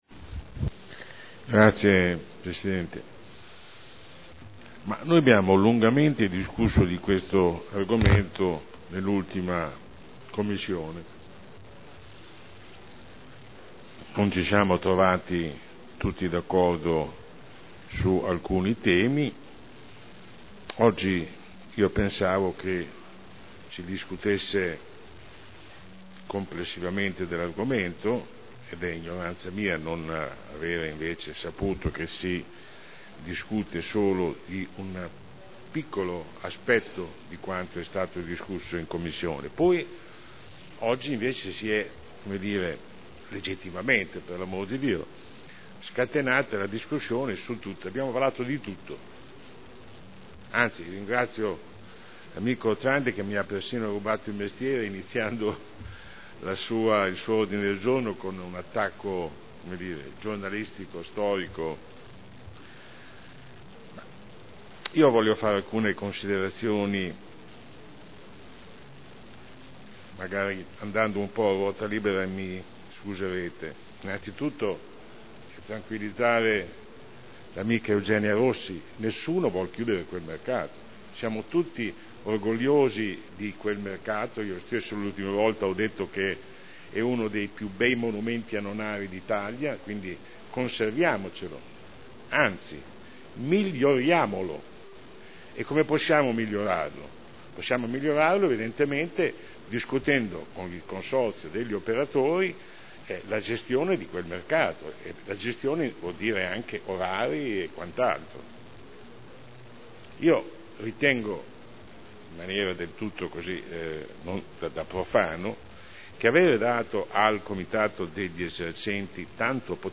Sandro Bellei — Sito Audio Consiglio Comunale
Seduta del 17/01/2011. Regolamento comunale del mercato coperto quotidiano di generi alimentari denominato “Mercato Albinelli” – Approvazione modifiche Discussione